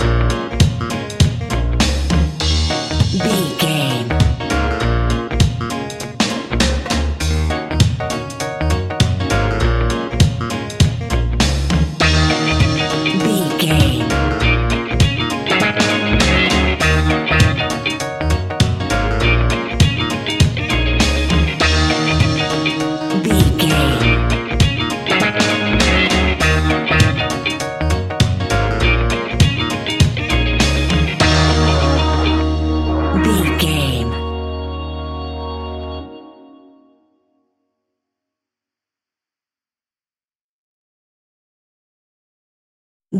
Aeolian/Minor
B♭
reggae instrumentals
laid back
chilled
off beat
drums
skank guitar
hammond organ
percussion
horns